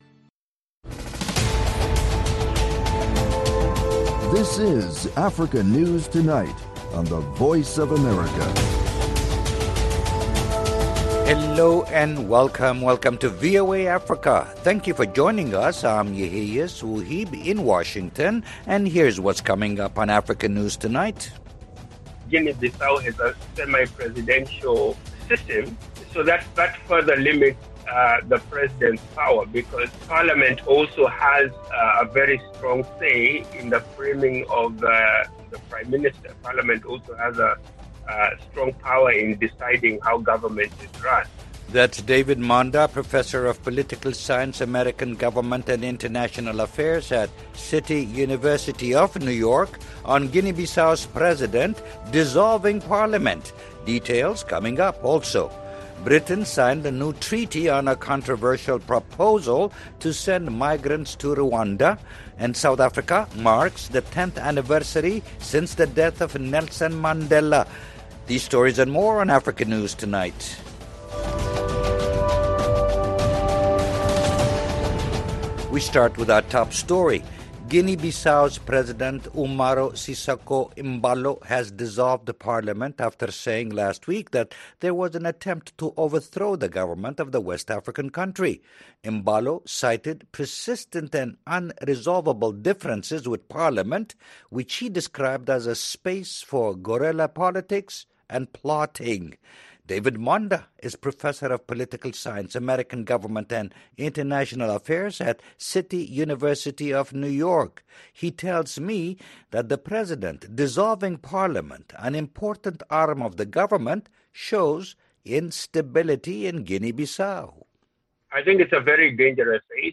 Africa News Tonight is a lively news magazine show featuring VOA correspondent reports, interviews with African officials, opposition leaders, NGOs and human rights activists. News feature stories look at science and technology, environmental issues, humanitarian topics, and the African diaspora.